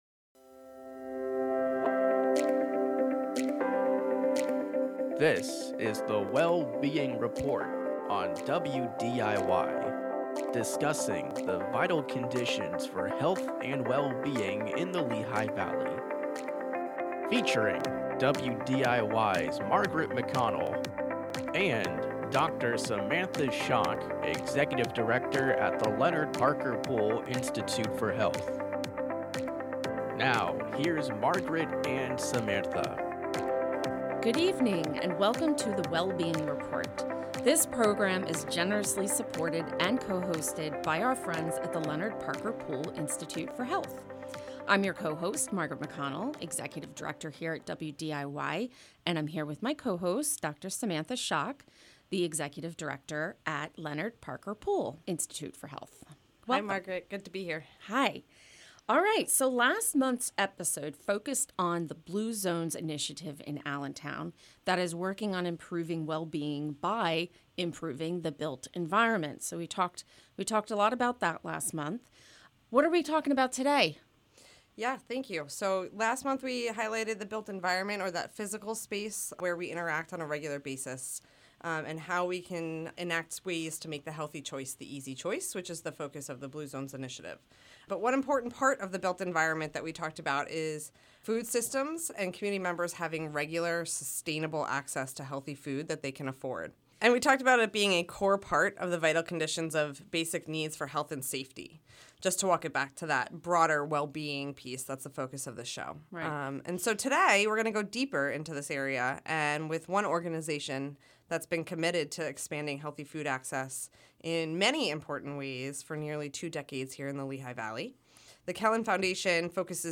host monthly roundtable discussions on the social determinants of health and the impact on communities here in the Lehigh Valley and beyond.